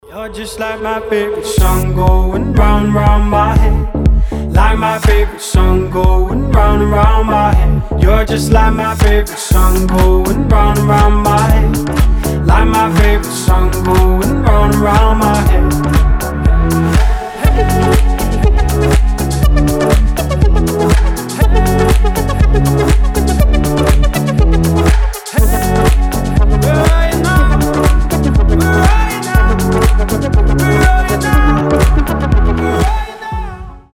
Клубные рингтоны
Клубняк